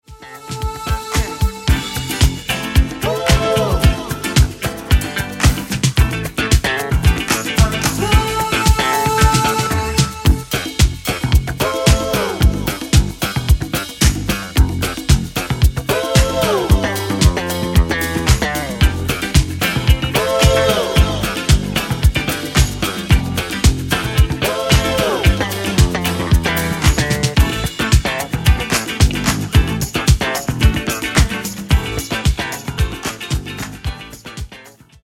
INSTRUMENTAL VERSION